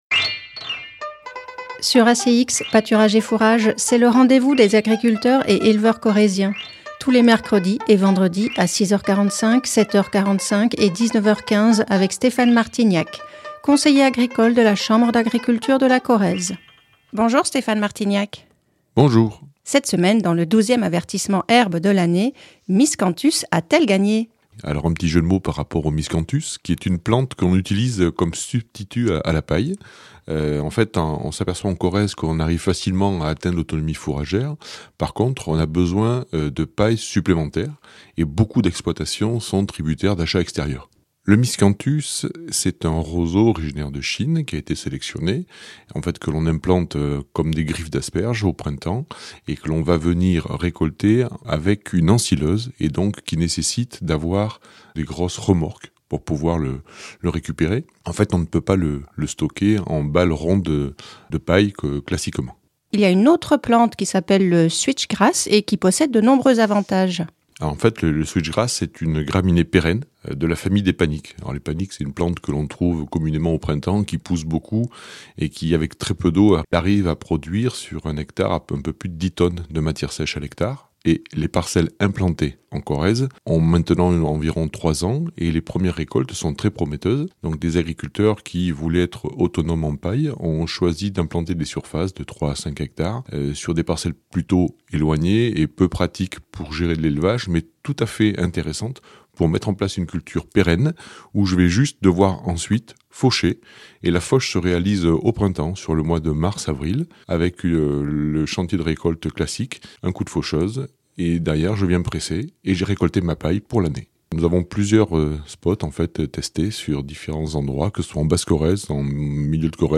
Genre : Abstract.